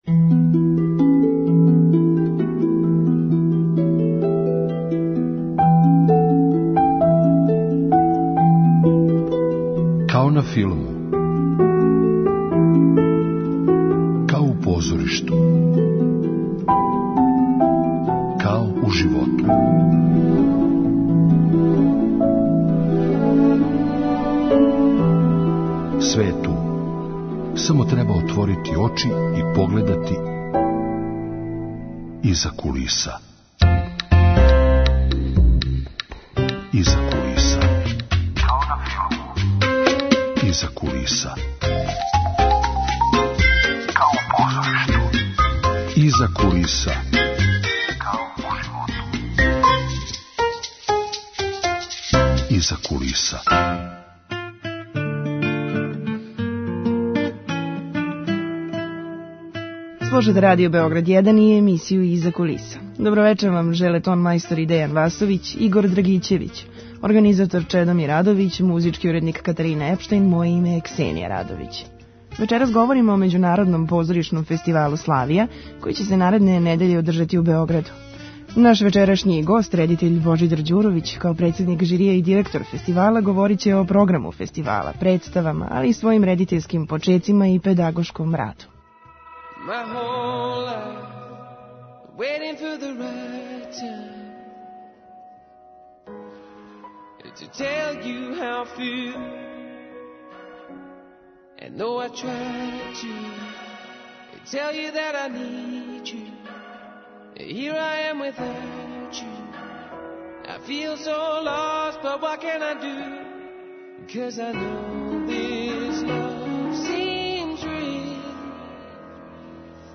Гост: редитељ